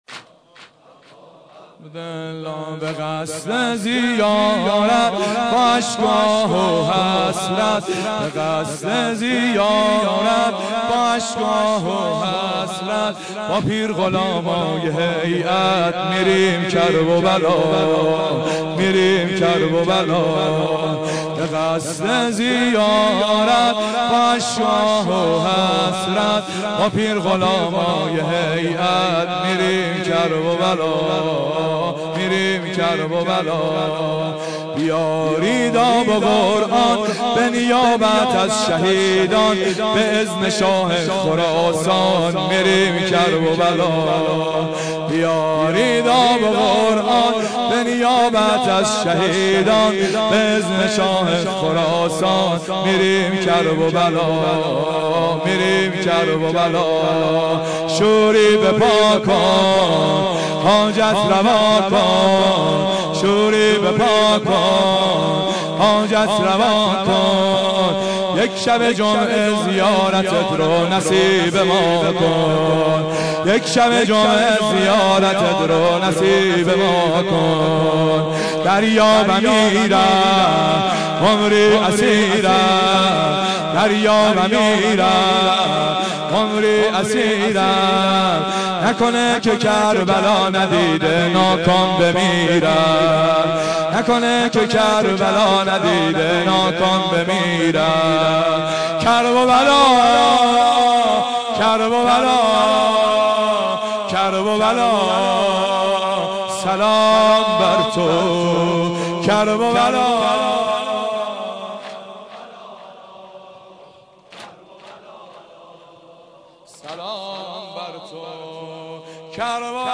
واحد شب تاسوعا 1391